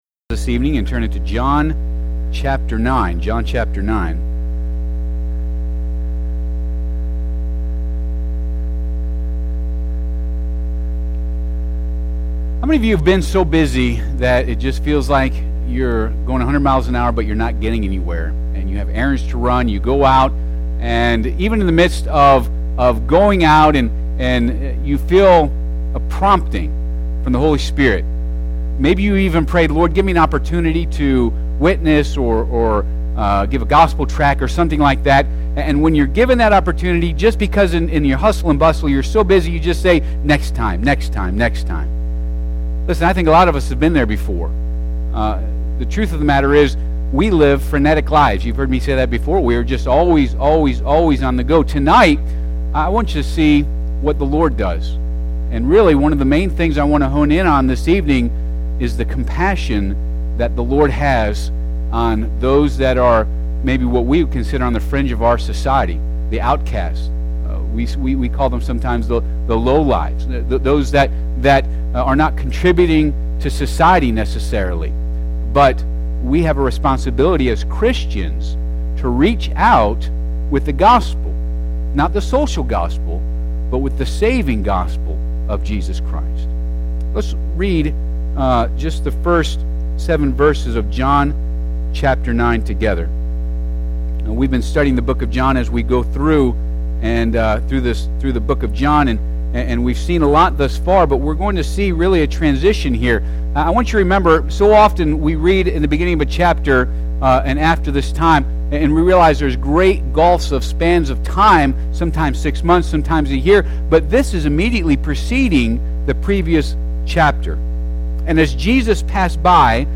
Passage: John 9 Service Type: Midweek Service